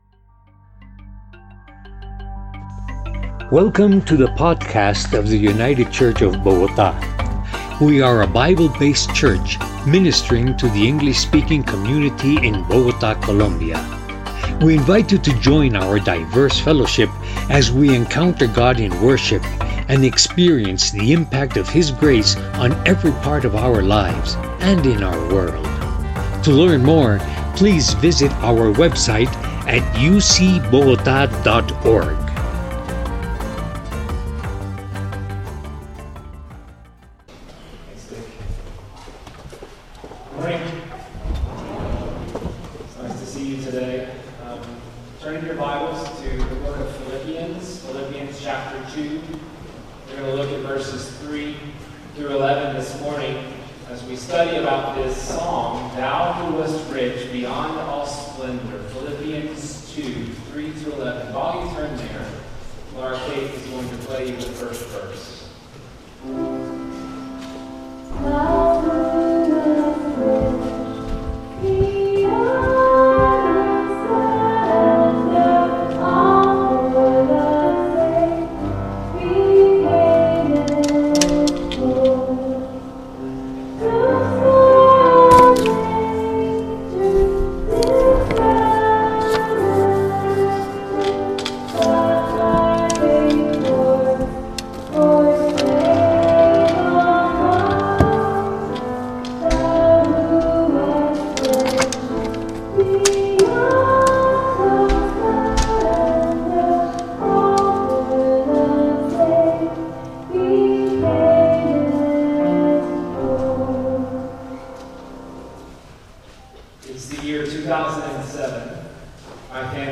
We apologize for the poor quality of this recording.